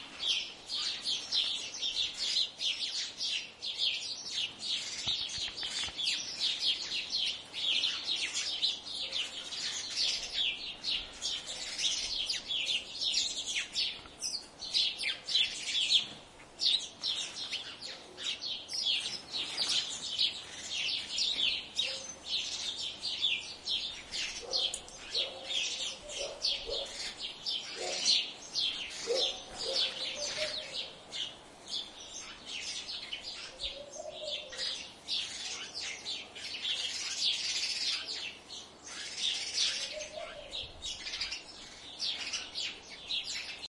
鸟类 晨间合唱 饲养 耶路撒冷
描述：早上喂养后院鸟的狂热。记录在耶路撒冷郊区。用Olympus LS10录制。
Tag: 场记录 耶路撒冷 上午 花园 饲养